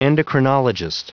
Prononciation du mot endocrinologist en anglais (fichier audio)
endocrinologist.wav